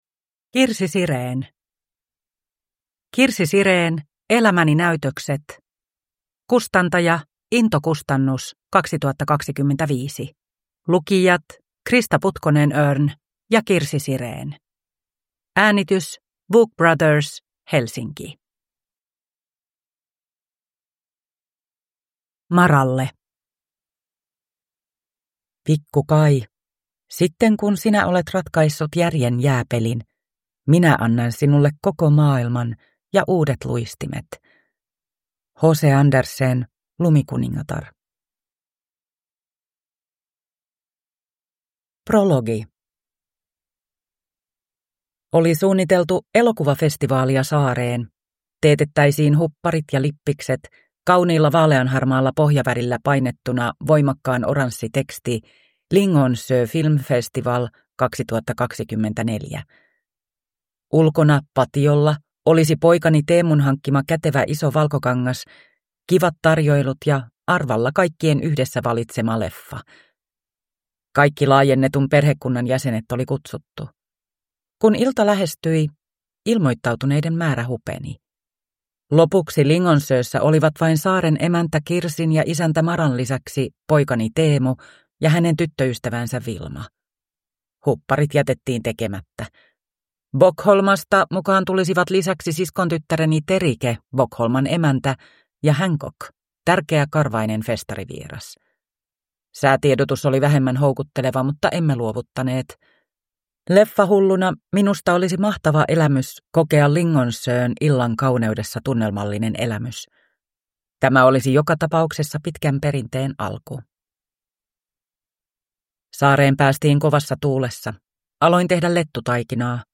Kirsi Siren – Elämäni näytökset – Ljudbok